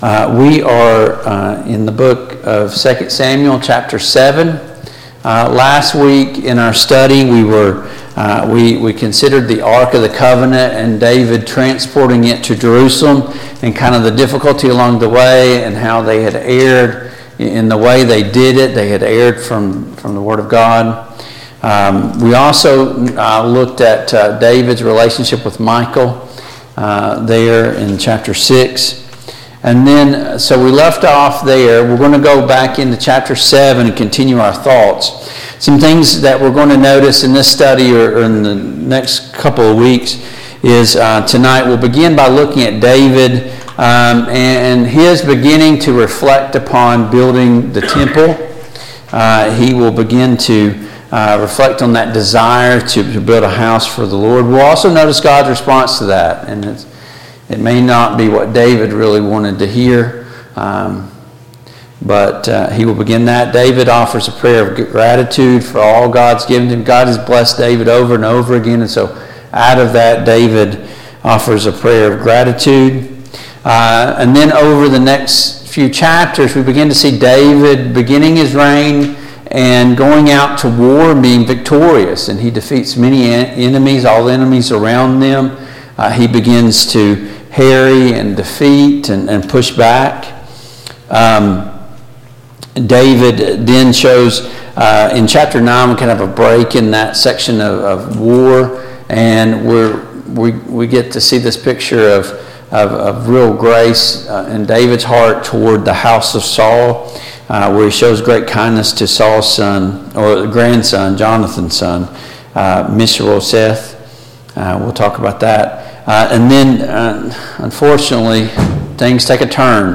The Kings of Israel Passage: II Samuel 7, II Samuel 8, II Samuel 9, II Samuel 10 Service Type: Mid-Week Bible Study